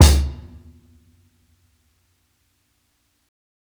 60s_KICK AND HH.wav